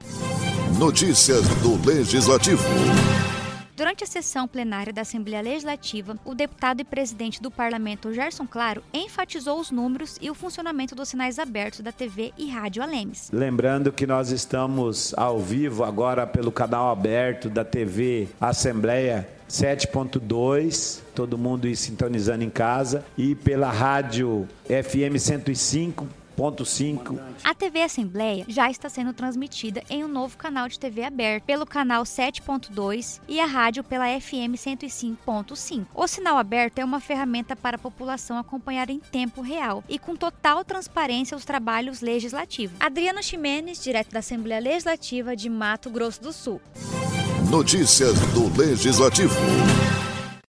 O deputado e presidente da Assembleia Legislativa de Mato Grosso do Sul (ALEMS), Gerson Claro (PP), falou durante a sessão plenária sobre os números e o funcionamento dos sinais da TV aberta canal 7.2 e a Rádio FM 105.5 ALEMS.